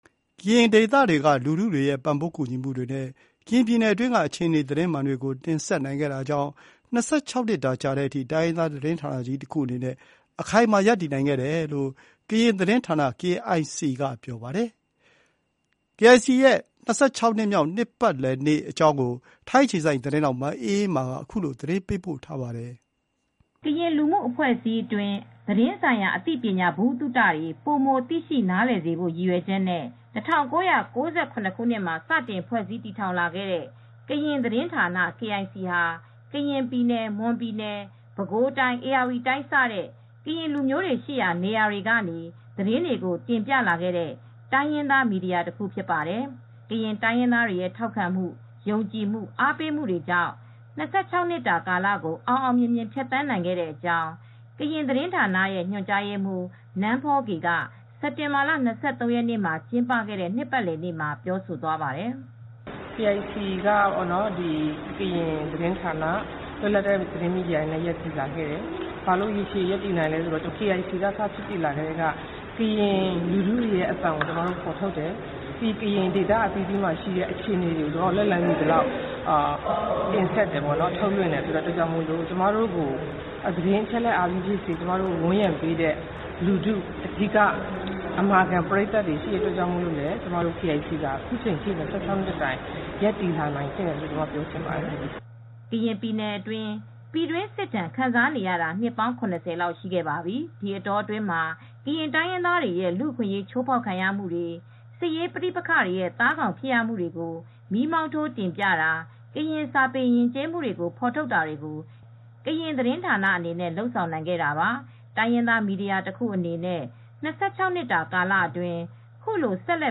ကရင်ဒေသတွေက လူထုတွေရဲ့ ပံ့ပိုးကူညီမှုတွေနဲ့ ကရင်ပြည်နယ်အတွင်းက အခြေအနေ သတင်းမှန်တွေကို တင်ဆက်နိုင်ခဲ့တာကြောင့် ၂၆ နှစ်တာကြာတဲ့အထိ တိုင်းရင်းသား သတင်းဌာနကြီးတခုအနေနဲ့ အခိုင်အမာ ရပ်တည်နိုင်ခဲ့တယ်လို့ ကရင်သတင်းဌာန (KIC) က ပြောပါတယ်။ KIC သတင်းဌာနရဲ့ ၂၆ နှစ်‌ မြောက် နှစ်ပတ်လည်နေ့ကို ထိုင်း-မြန်မာနယ်စပ်တနေရာမှာ စက်တင်ဘာလ ၂၃ ရက်နေ့က ကျင်းပခဲ့တာပါ။